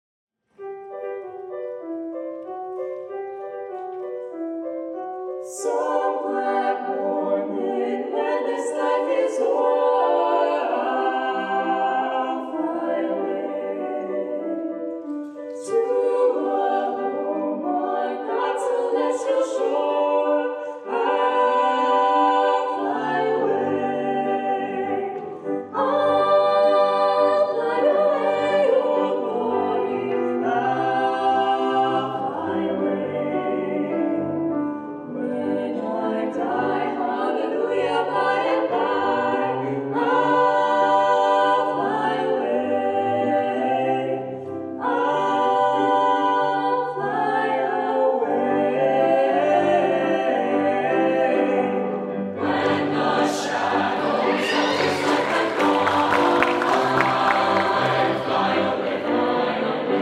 four curricular choirs and two a cappella ensembles
Sing With Heart: Spring Concert, 2019
With: Vocal Ensemble